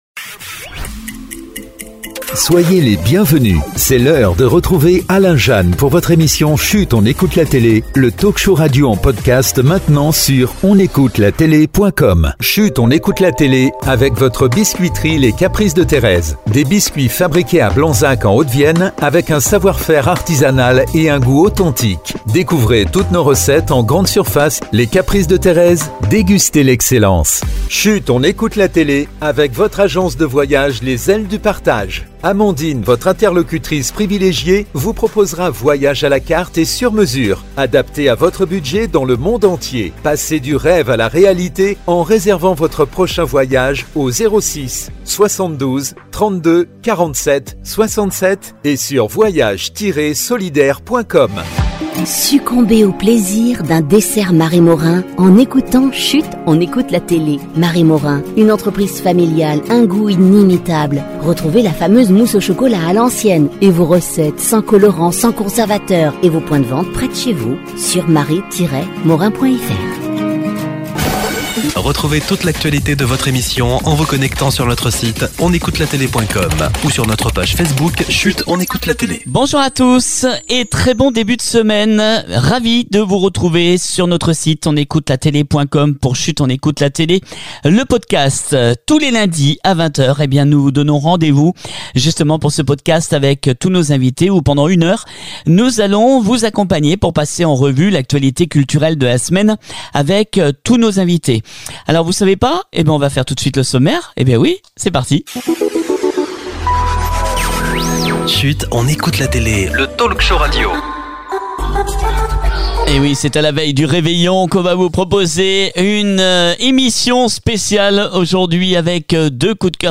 On se retrouve ce lundi 23 Décembre pour une nouvelle émission Chut on écoute la télé inédite avec de nombreux invités, on parle de